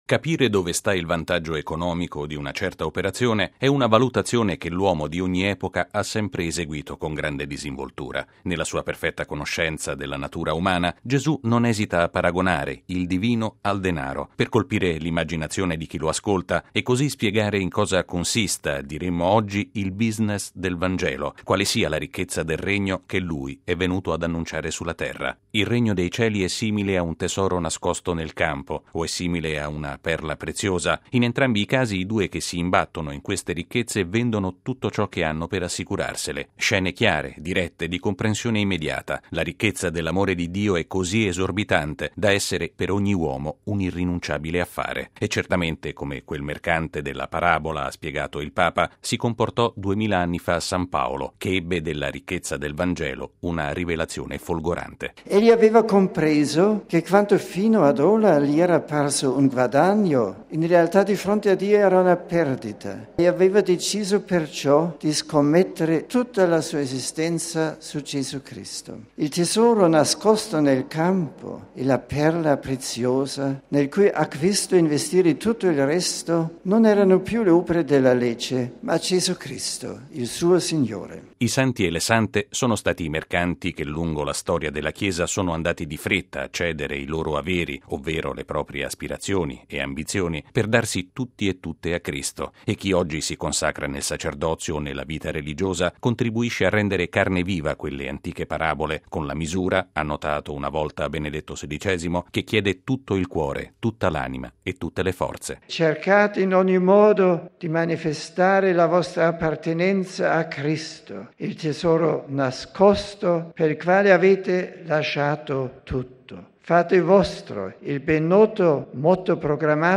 Alcune riflessioni del Papa ritornano in questo servizio